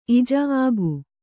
♪ ijā āgu